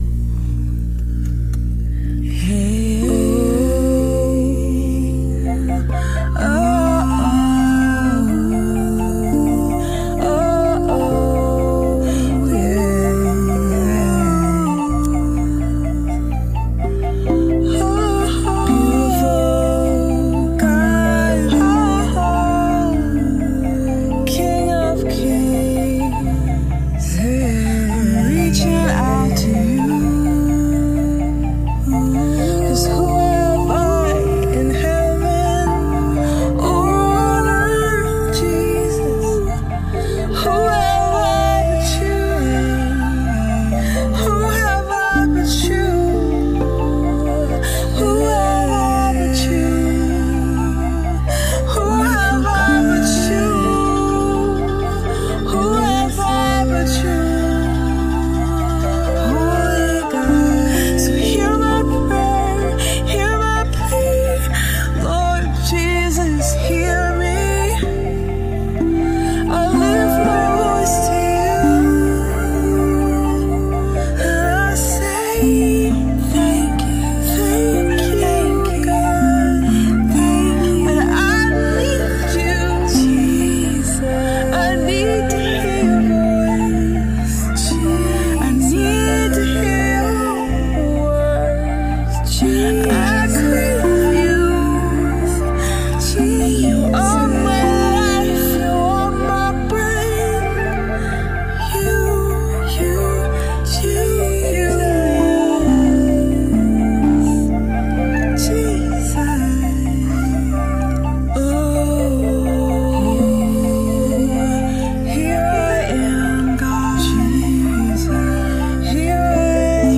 Spontaneous: Hear My Prayer Rav Vast & Beats Sessions
improvised worship
Rav vast drum
spontaneous worship